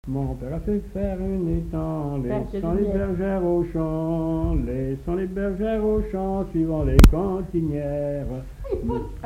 Chant de conscrits
Moutiers-sur-le-Lay
Chants brefs - Conscription